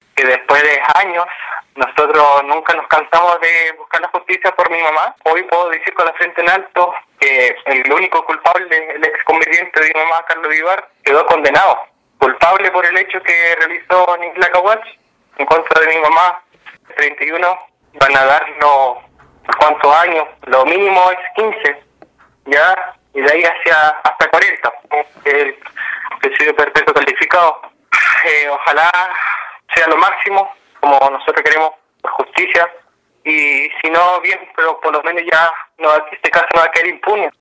La fiscal Pamela Salgado, de la Fiscalía Regional de Los Lagos, manifestó su satisfacción por lo resuelto por los jueces y reiteró que el ministerio Público está solicitando al tribunal Presidio Perpetuo Calificado.